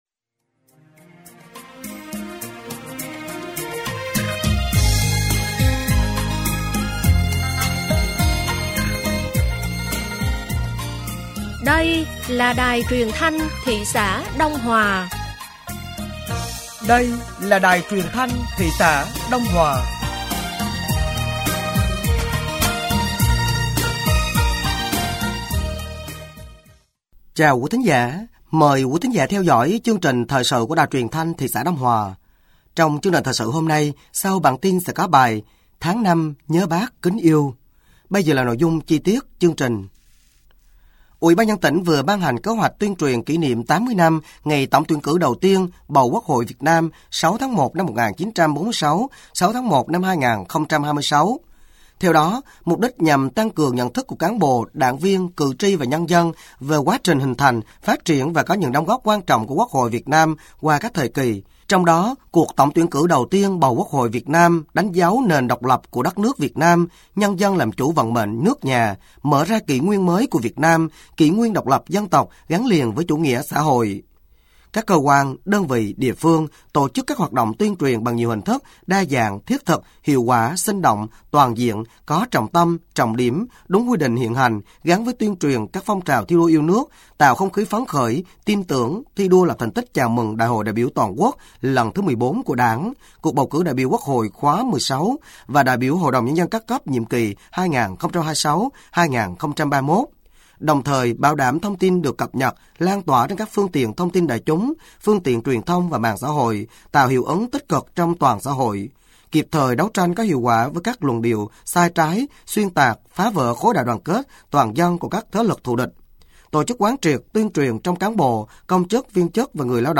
Thời sự tối ngày 17 và sáng ngày 18 tháng 5 năm 2025